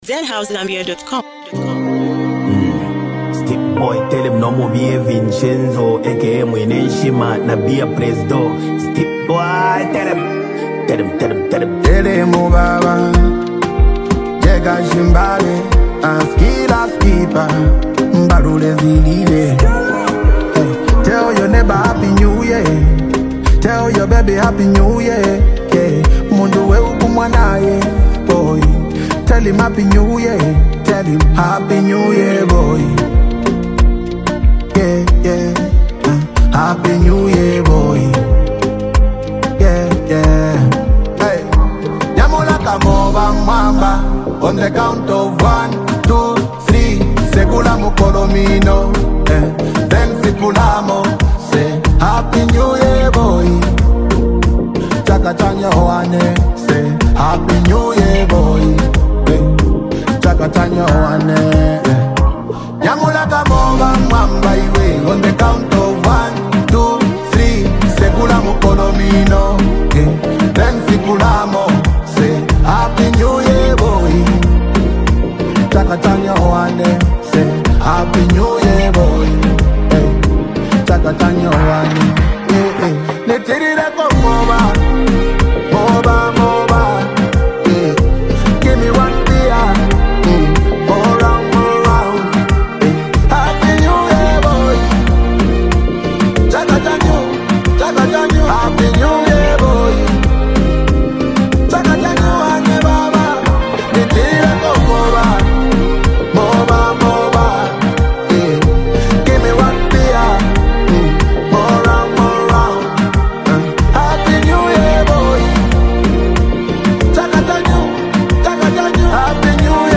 delivering uplifting vibes and positive energy.